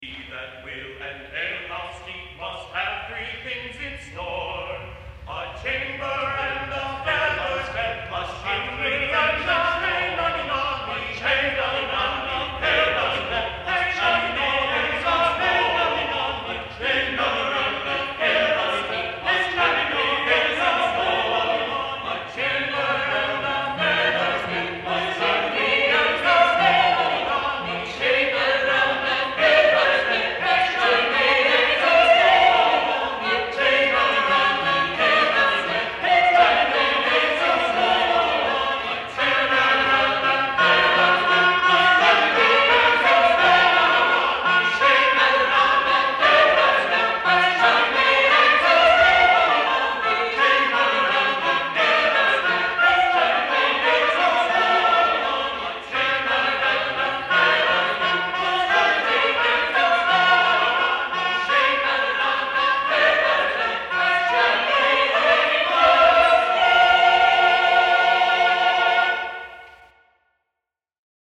It is indeed a round, and, keeping our priorities straight, it was the first item in our ‘Book of Trades’ . . . that of the publican.